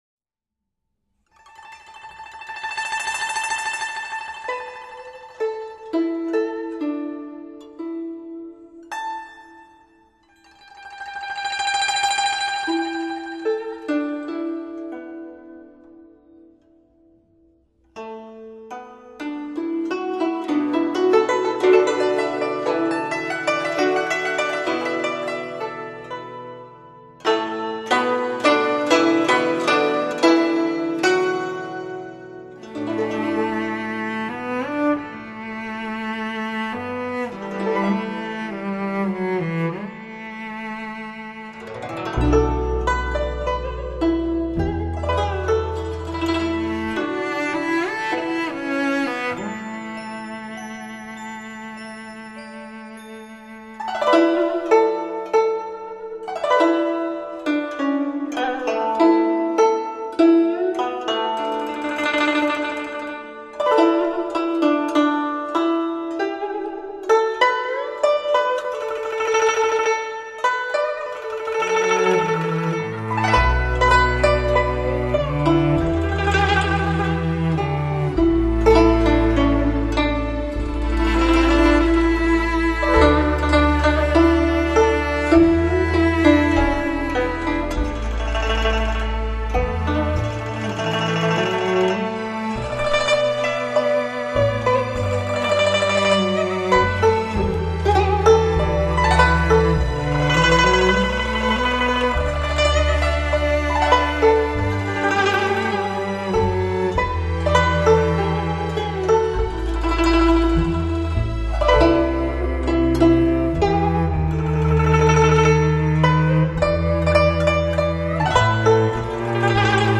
类 　 别：DSD